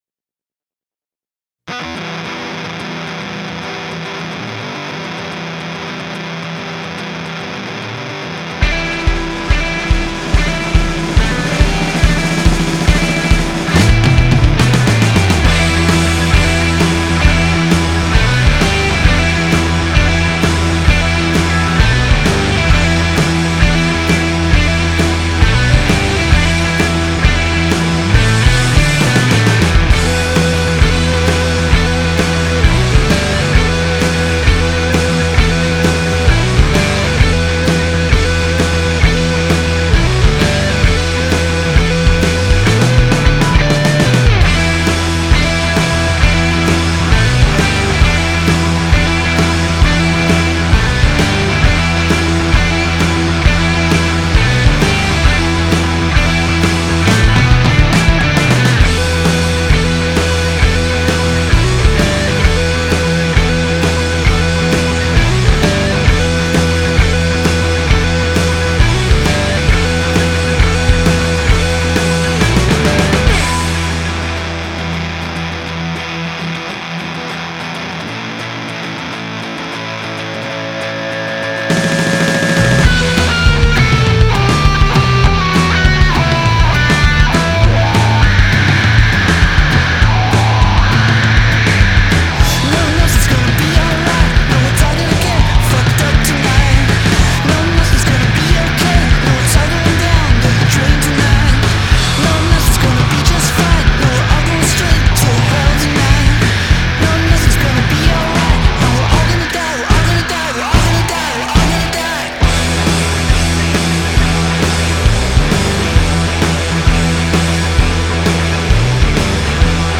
Genre: Indie, Rock